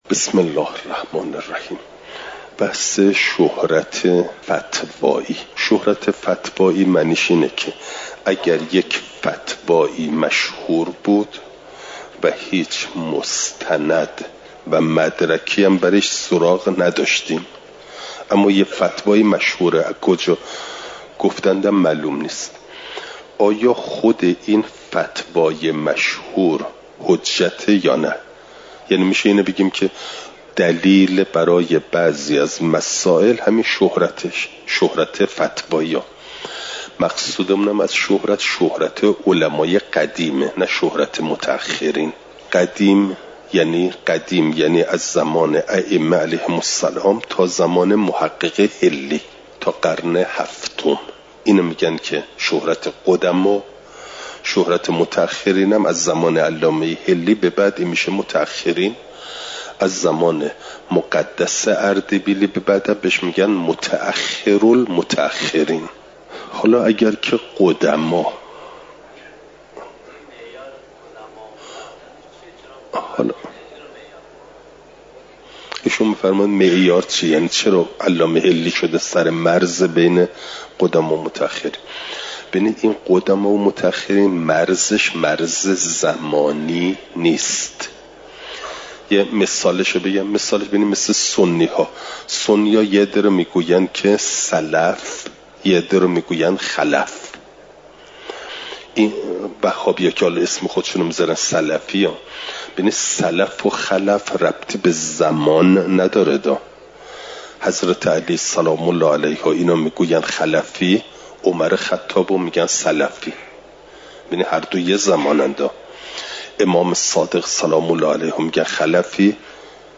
امارات؛ قطع و ظن (جلسه۱۸) – دروس استاد